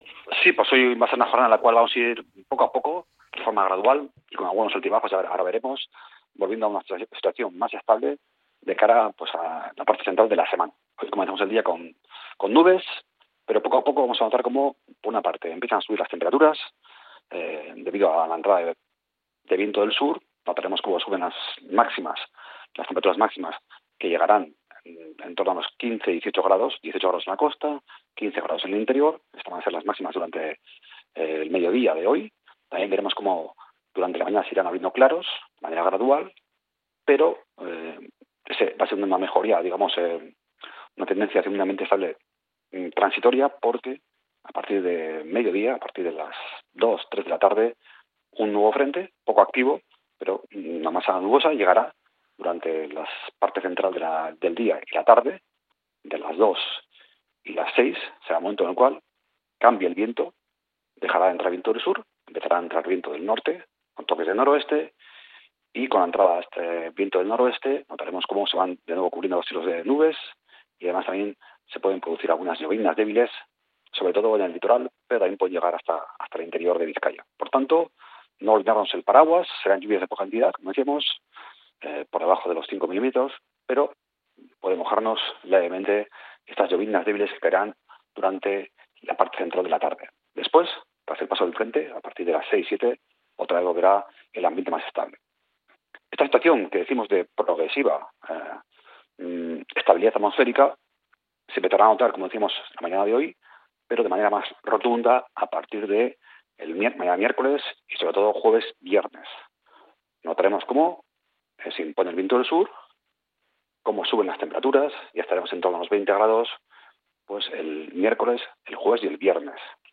El pronóstico del tiempo en Bizkaia para este 14 de abril